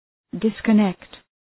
{,dıskə’nekt}